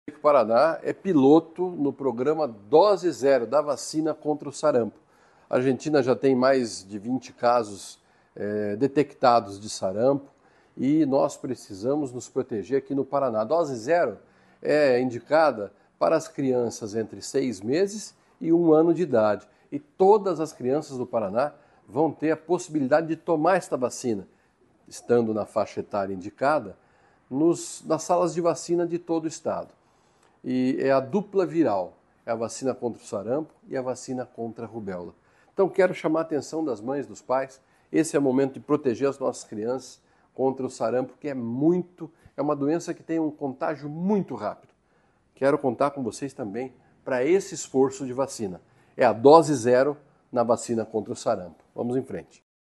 Sonora do secretário da Saúde, Beto Preto, sobre a implantação da “Dose Zero” da vacina contra o sarampo